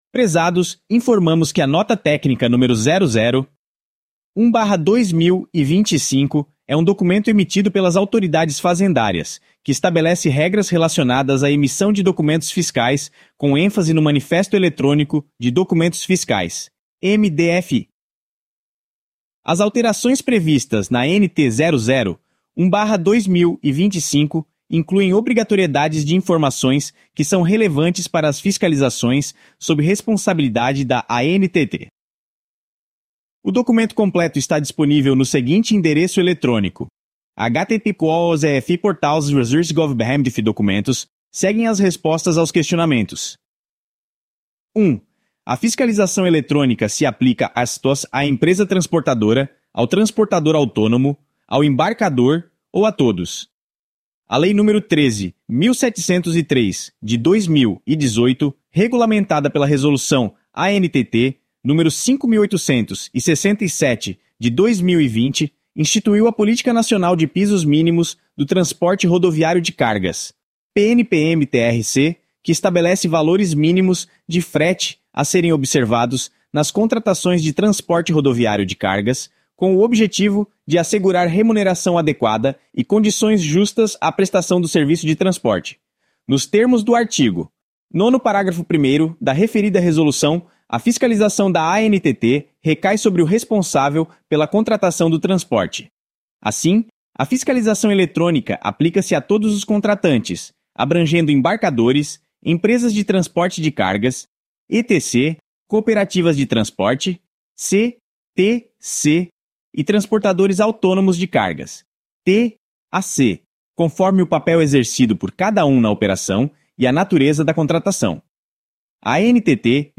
O áudio gerado por Inteligência Artificial podem conter falhas em pronúncias.